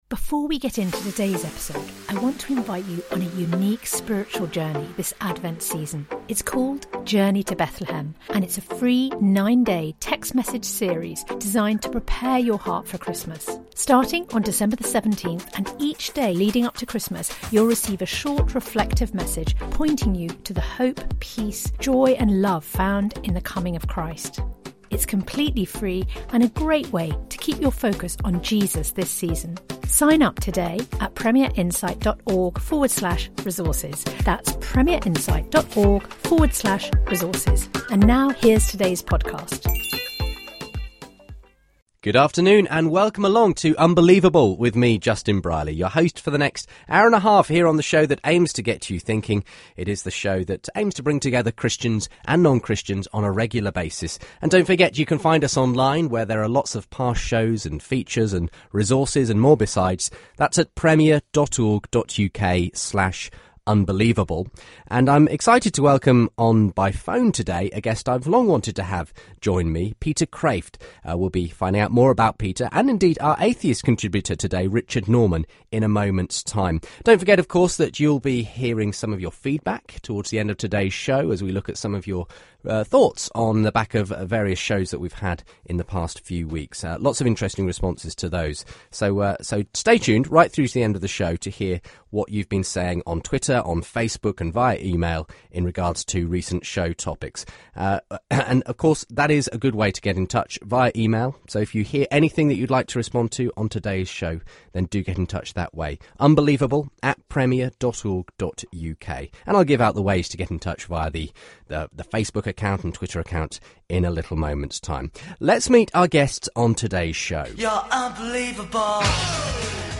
Peter Kreeft debates the argument from desire - Unbelievable?